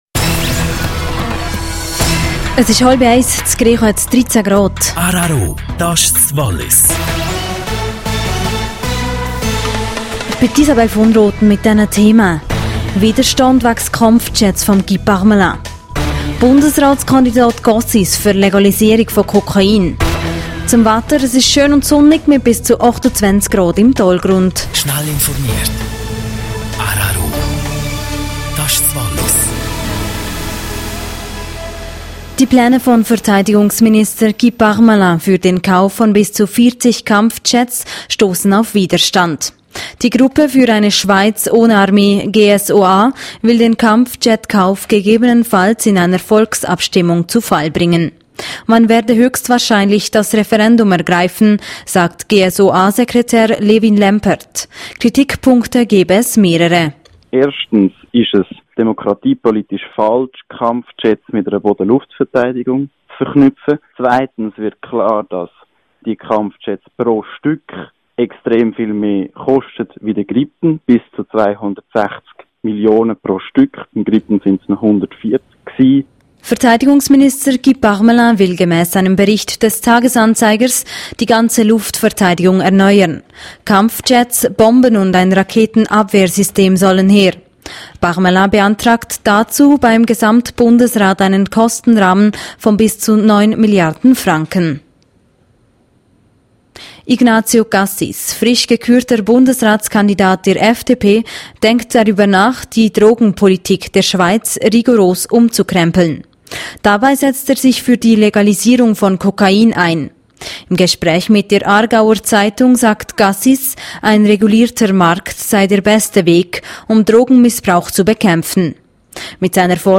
12:30 Uhr Nachrichten (2.88MB)